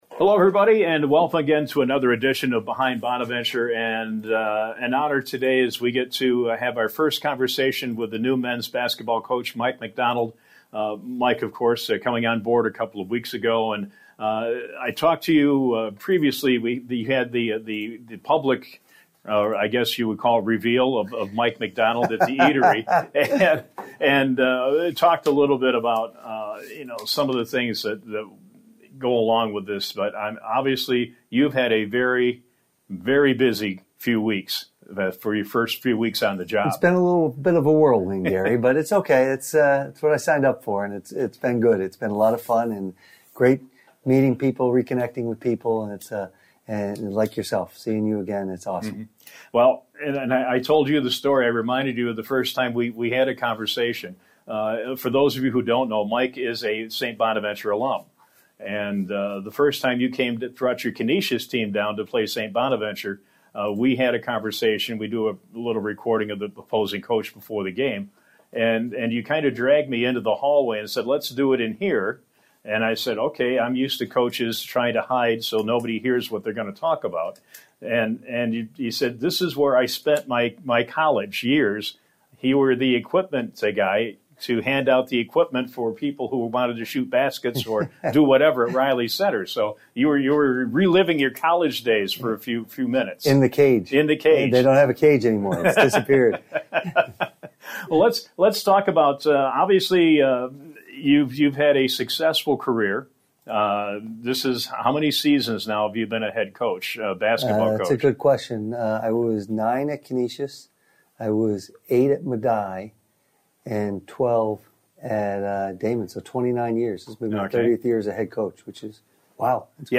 in-depth discussion